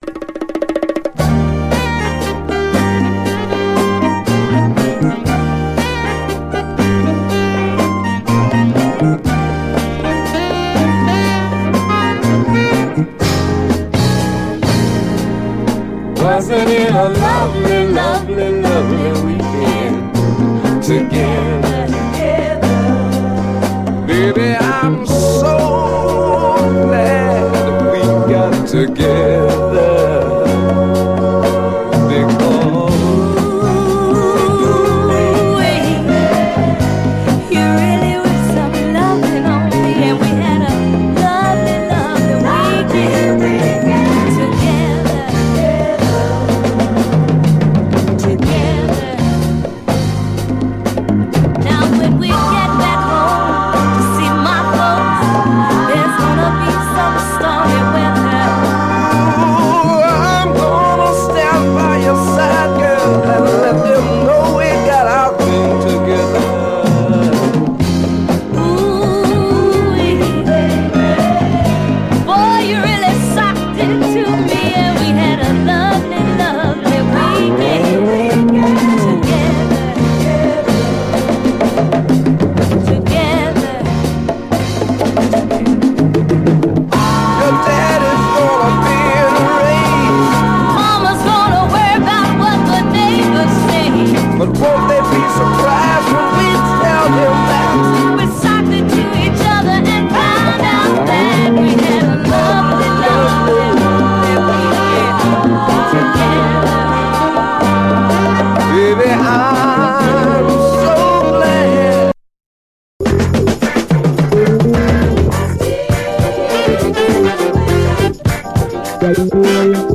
これでもかと甘酸っぱいメロディー・ラインが続く青春チューン